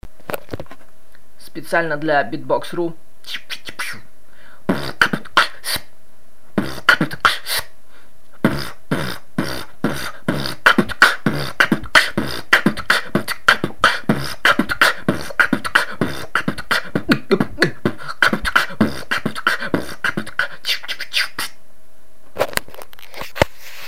P.S K это не инв K а что другое хз как назвать но по звуку это хендклеп только делаю иго на выдохе
P.P.P.S Запись да и битую я чото убого так что сильно не пинать=)
Норм так бит) только какой-то однообразный.